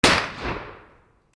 銃 実弾射撃
/ H｜バトル・武器・破壊 / H-05 ｜銃火器